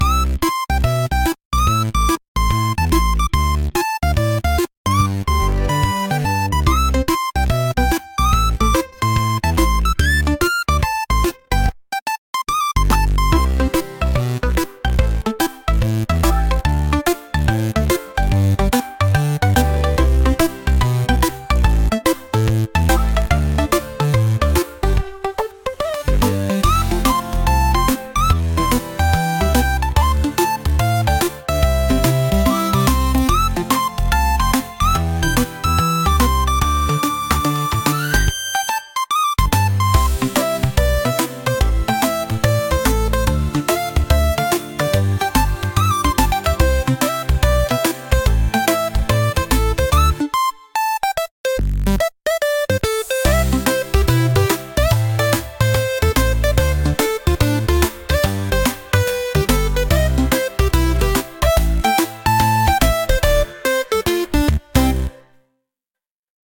日常感のあるピコピコ8bitサウンドです。